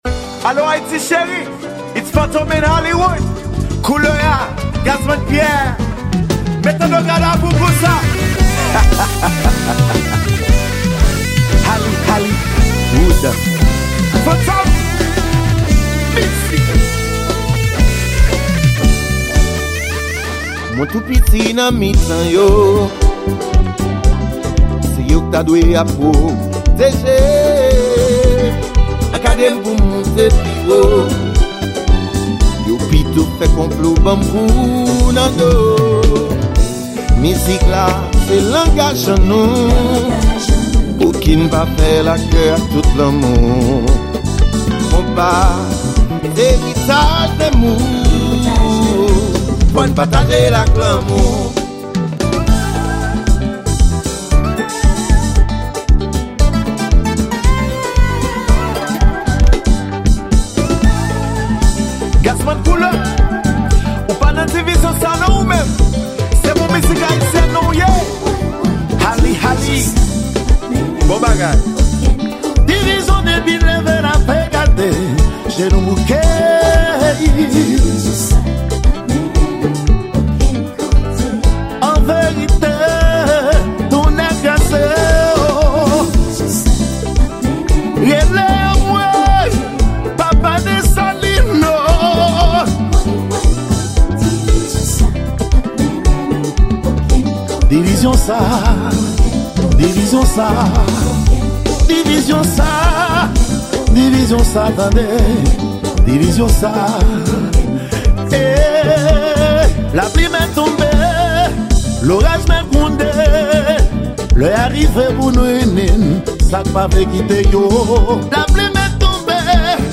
Genre: konpa.